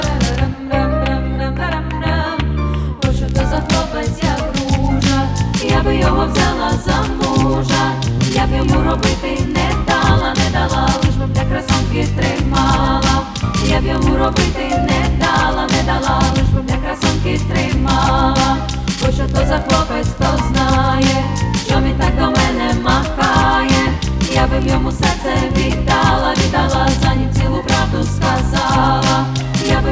Жанр: Фолк-рок / Украинские
# Traditional Folk